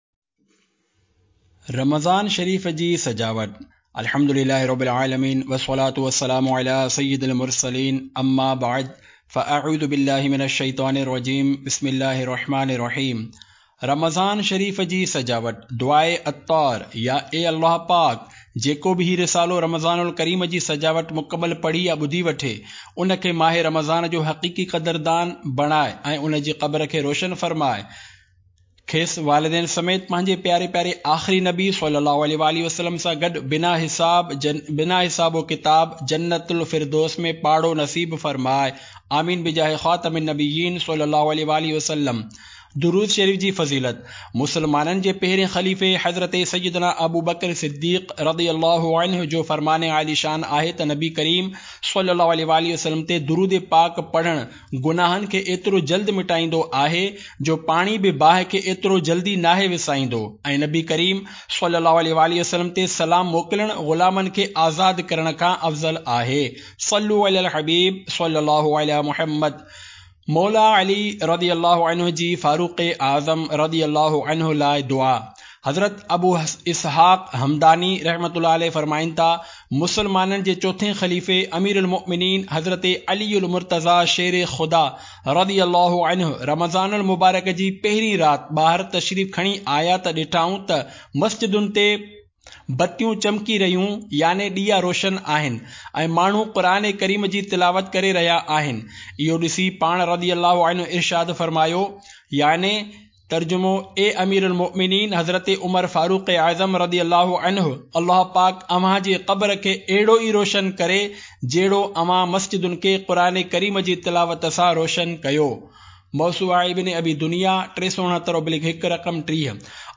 Audiobook - Ramazan ul Kareem Ki Sajawat (Sindhi)